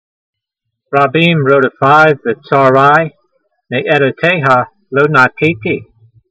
v157_voice.mp3